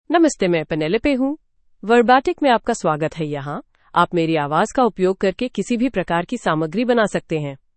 Penelope — Female Hindi (India) AI Voice | TTS, Voice Cloning & Video | Verbatik AI
Penelope is a female AI voice for Hindi (India).
Voice sample
Female
Penelope delivers clear pronunciation with authentic India Hindi intonation, making your content sound professionally produced.